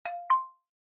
Lower volume of call connect/disconnect sound. ... It's now closer in amplitude to the ringing sound.